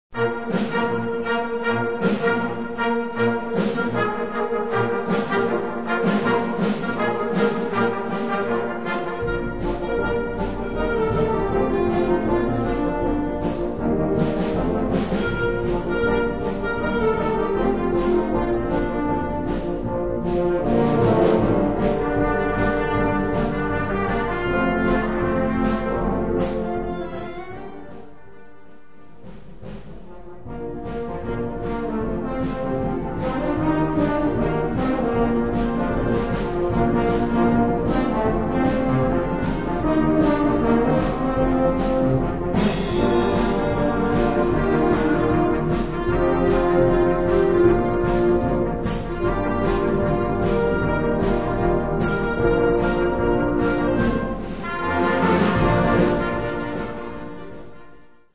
6:12 Minuten Besetzung: Blasorchester Zu hören auf